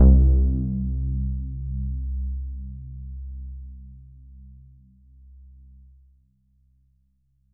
SOUND  C1.wav